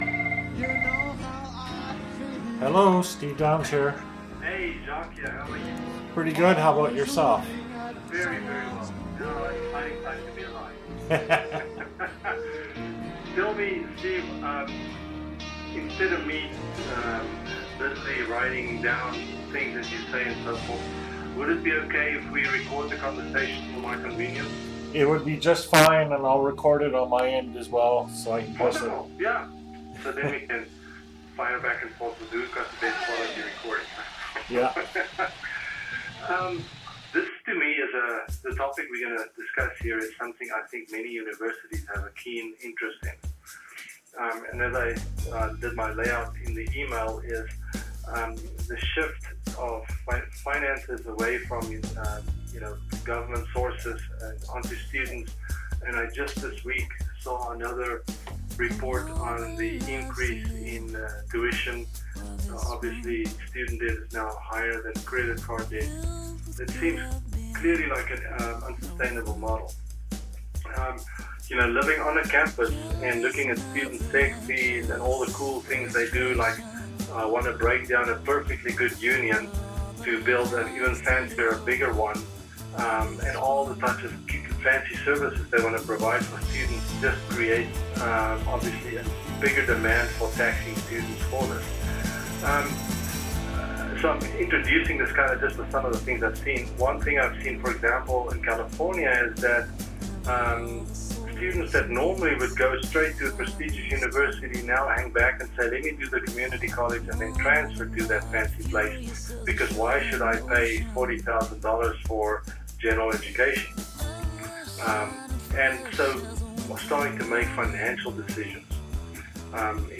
Sorry about the music track in the background.